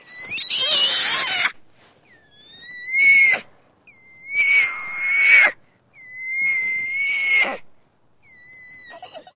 Визг орангутана